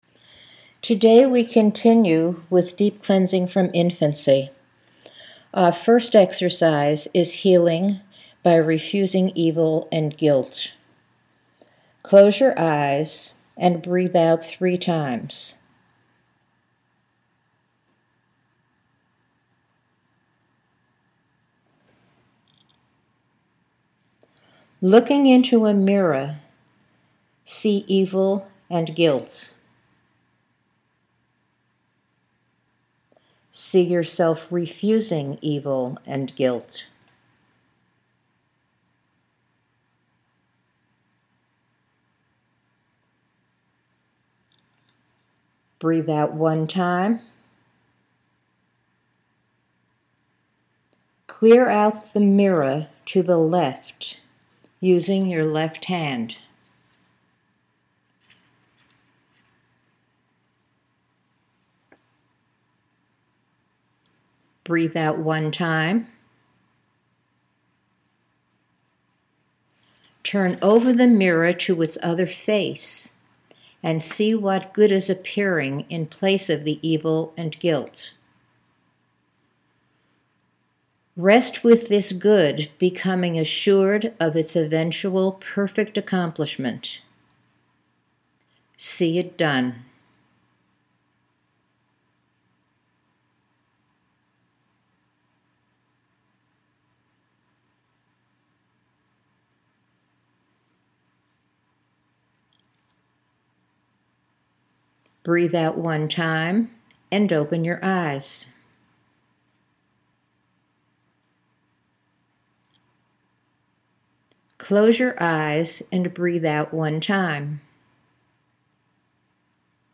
It isn’t completed until you hear “breathe out one time” followed by the bing and beep sounds